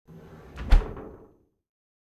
Fridge Door Close Wav Sound Effect #3
Description: The sound of closing a fridge door
Properties: 48.000 kHz 16-bit Stereo
Keywords: fridge, refrigerator, freezer, door, close, closed, closing
fridge-door-close-preview-3.mp3